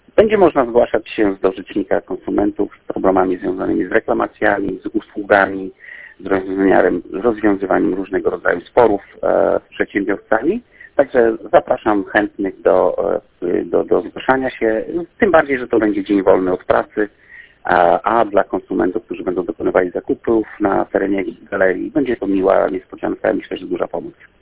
Mówił Dariusz Dracewicz – Powiatowy Rzecznik Praw Konsumentów.